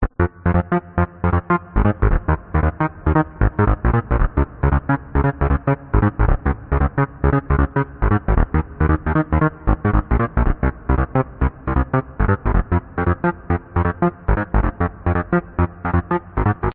循环播放 " 科技循环播放124bpm
描述：这是个延迟的、有旋律的器乐循环。可能对最小的技术性音乐有用。
Tag: 最小 环路 声音效果 高科技 电子乐 器乐 124bpm 延迟 声音